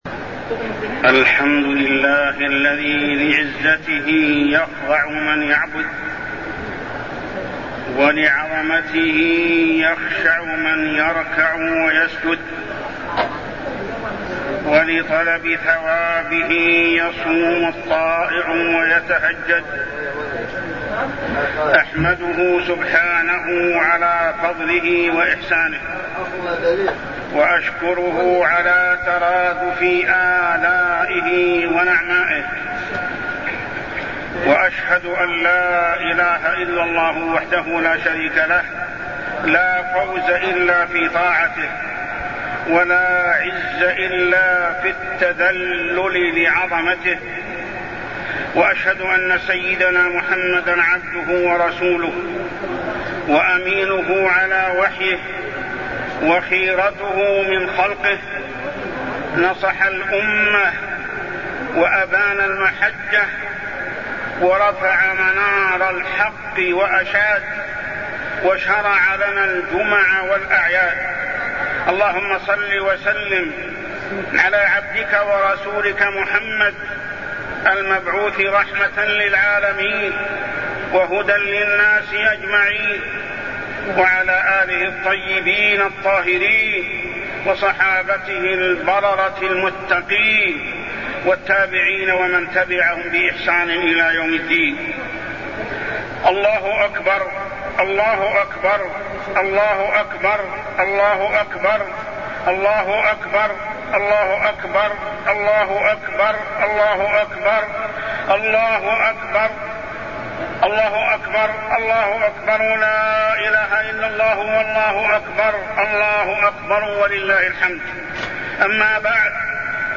خطبة عيد الفطر -الدعوة إلى الله على علم وبصيرة
تاريخ النشر ١ شوال ١٤٢٠ هـ المكان: المسجد الحرام الشيخ: محمد بن عبد الله السبيل محمد بن عبد الله السبيل خطبة عيد الفطر -الدعوة إلى الله على علم وبصيرة